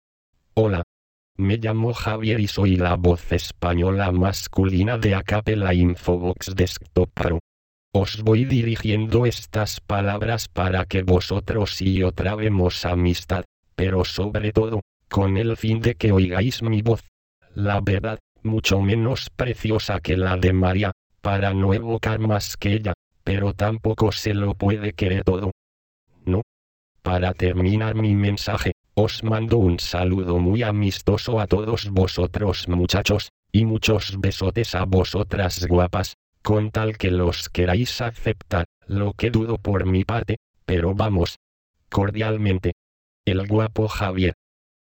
Texte de démonstration lu par Javier (Nuance RealSpeak; distribué sur le site de Nextup Technology; homme; espagnol mexicain)